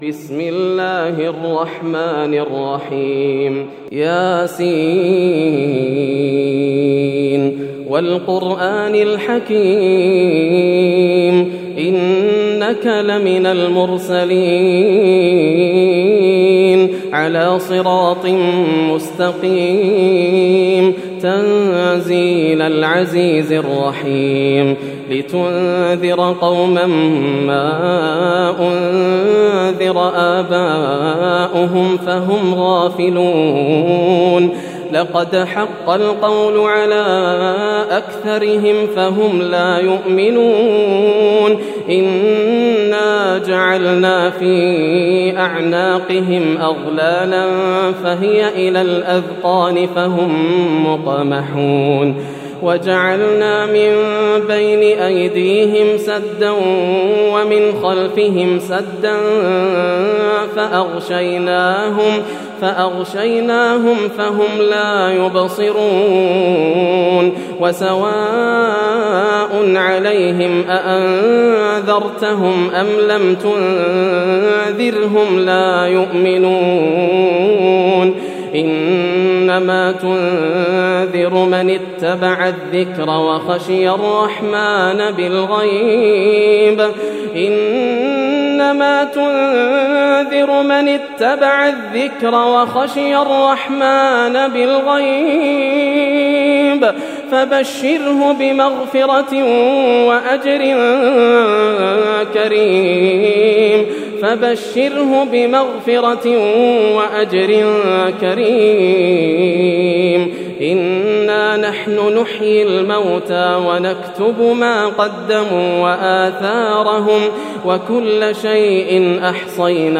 سورة يس > السور المكتملة > رمضان 1431هـ > التراويح - تلاوات ياسر الدوسري